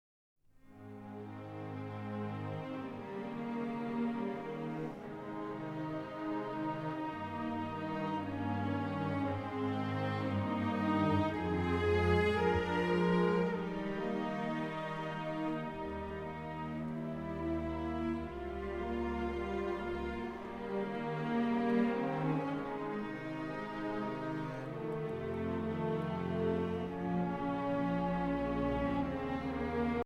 für Horn und Orchester.
Solo Horn in F
Flöte 1
Oboe 1
Klarinette 1 in B
Fagott 1
Trompete 1 in B
Posaune 1
Pauke B Es
5 Violine 1
3 Viola
3 Violoncello
2 Kontrabass